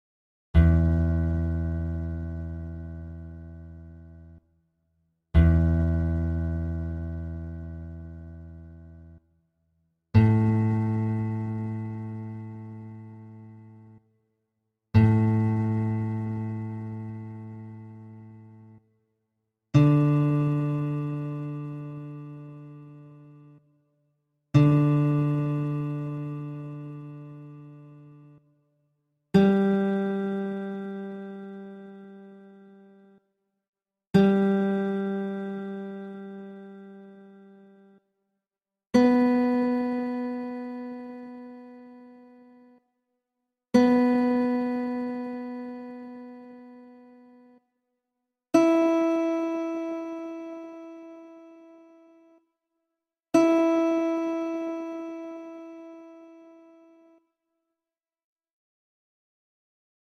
Vision Music's "Guitar Tune Up!"
guitartuneup.mp3